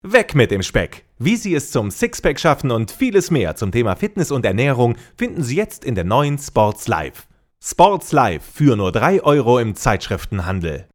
deutscher Sprecher, off Sprecher, TV Radio Moderator, Werbesprecher, Trailer, Hörbuch, Doku, Videospiele, div.
Sprechprobe: Werbung (Muttersprache):